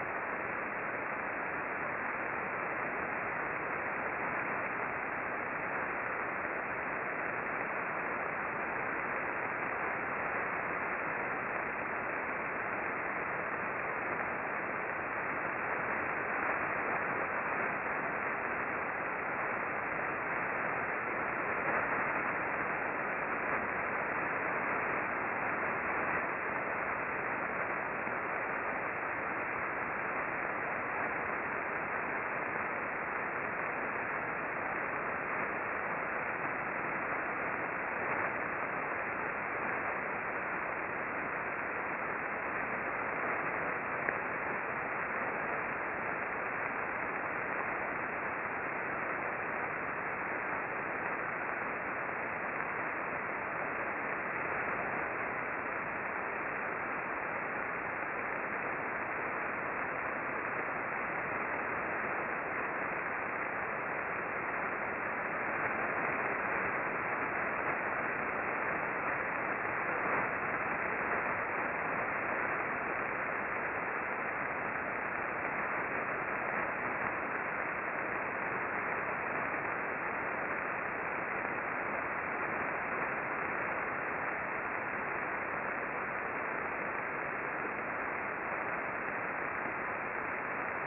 The above chart shows some strong S-bursts and L-bursts.